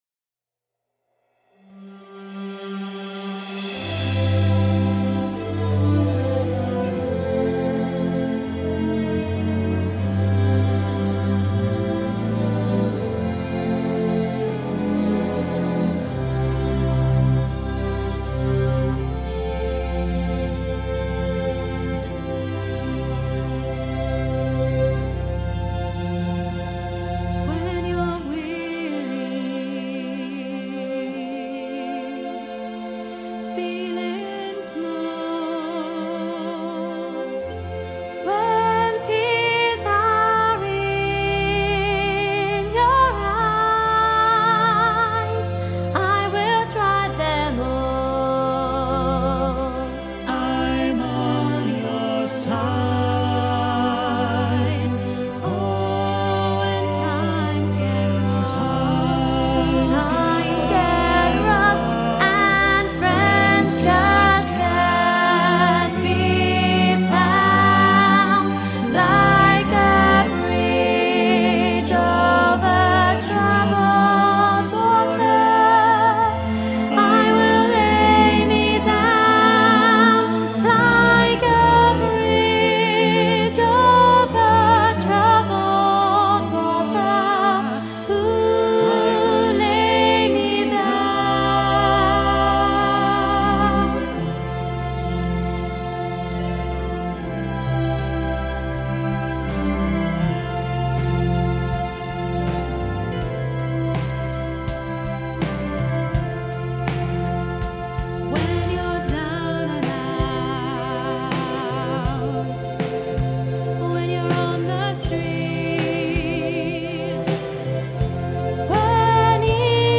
bridge-over-troubled-water-soprano-half-mix.mp3